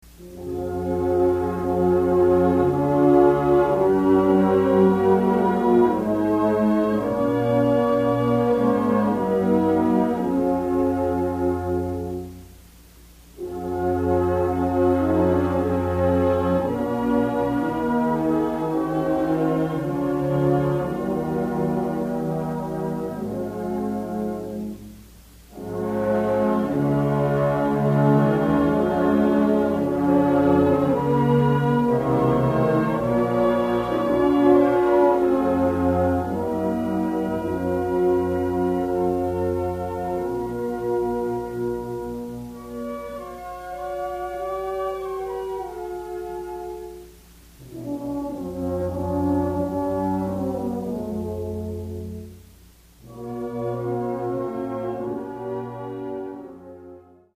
Genre: Band
A beautiful arrangement for band.
Flute 1/2
Oboe
Clarinet in Bb 1, 2, 3
Bass Clarinet
Bassoon
Alto Saxophone 1/2
Tenor Saxophone
Baritone Saxophone
Trumpet in Bb 1/2
Horn in F 1/2
Trombone 1/2
Euphonium
String Bass
Tuba